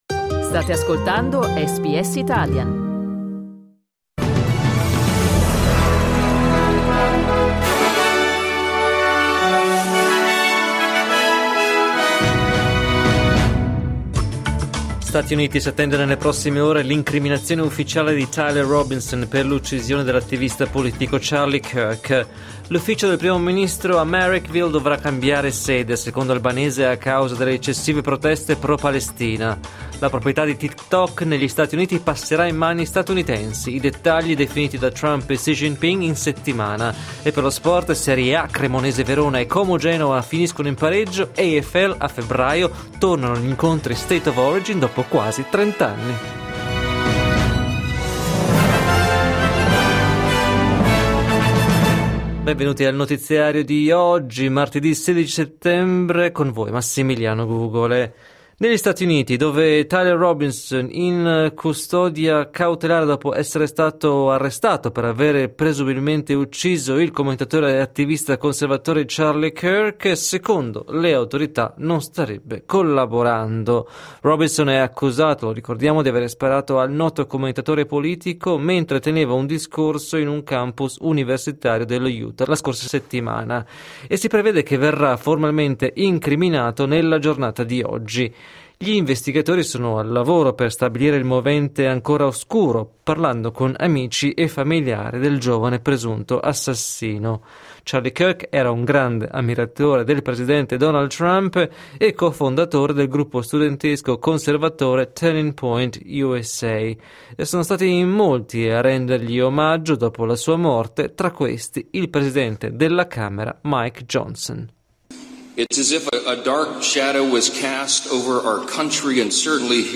Giornale radio martedì 16 settembre 2025
Il notiziario di SBS in italiano.